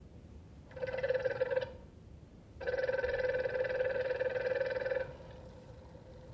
Bruit UI pompe de relevage
Je m'étais préparé à avoir du bruit dans mon UI Mitsubishi, mais pas du tout à ce bruit-là.
C'est un bruit aigu. L'installateur m'a dit que c'était l'écoulement de l'eau.
La pompe est une Refco installée à l'intérieur du split.